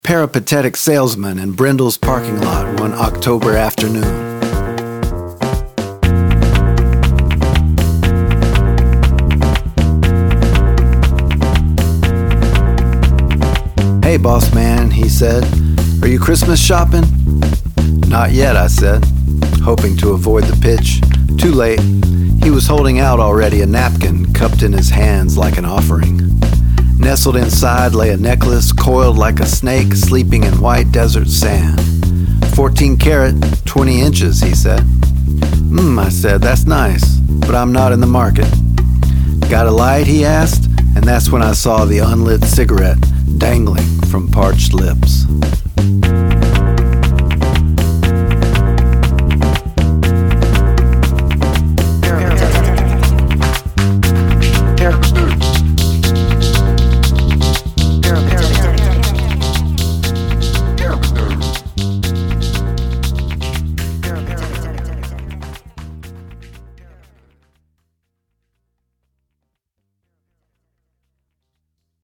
In this short poem, the words “Not yet I said / hoping to avoid the pitch” became, after a bit of editing, the piano phrase you hear in the song.